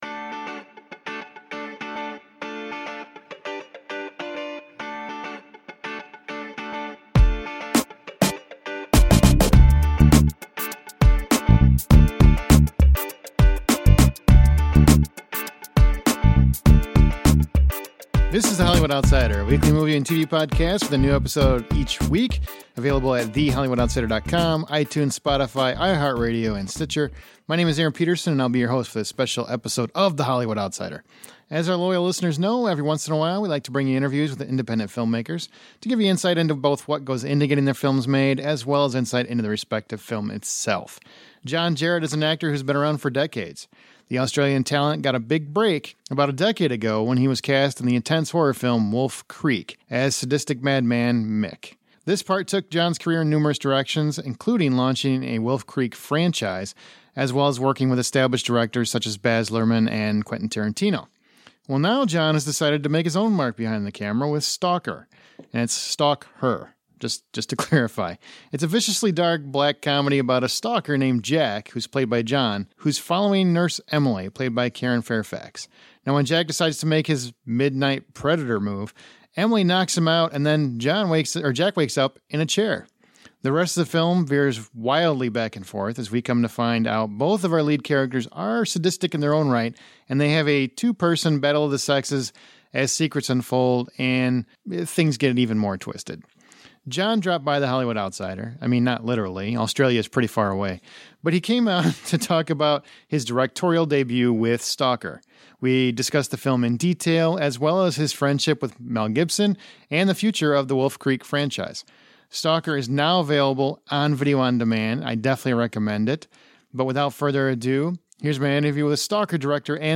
Listen to our exclusive interview with StalkHer director and star, John Jarratt, as we discuss the film, his friendship with Mel Gibson, and the future of Wolf Creek.